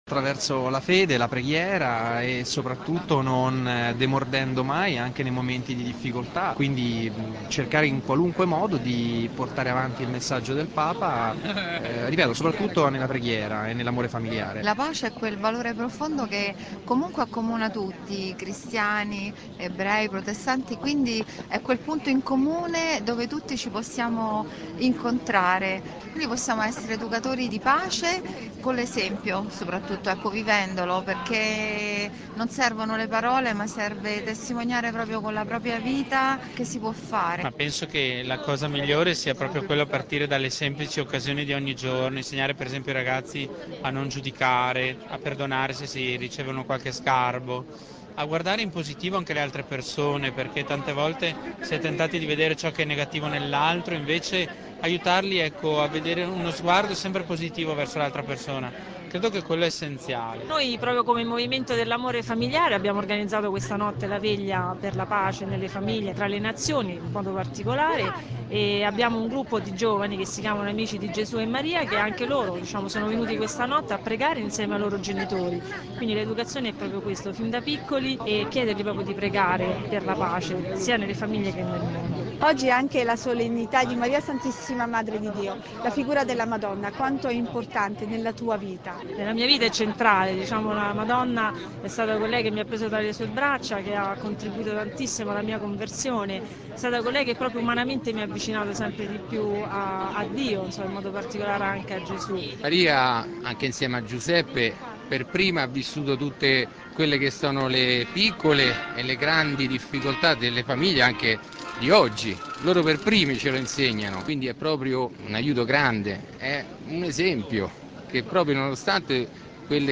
Migliaia di pellegrini in Piazza San Pietro per il primo Angelus dell’anno (da Radio Vaticana del 1 gennaio)